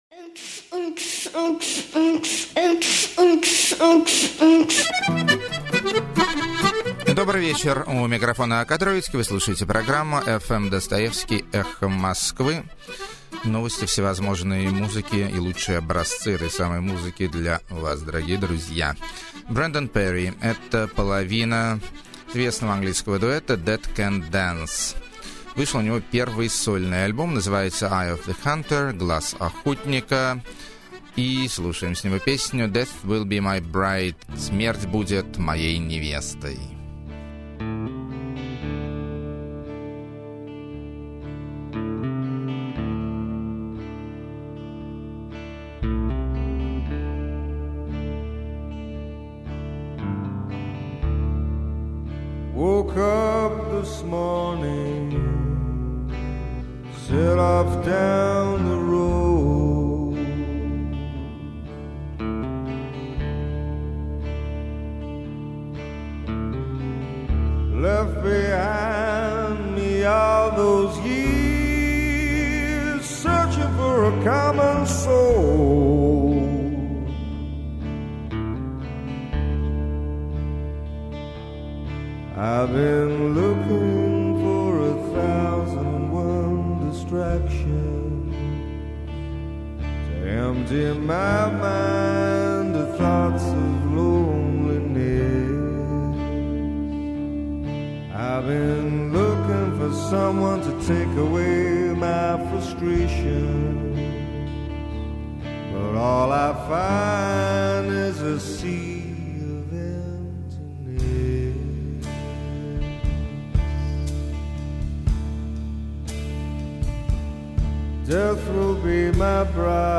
Свинг С Легким Футуристическим Привкусом.
Футуризм С Легким Свинговым Привкусом.
Народная Карпатская Мистика.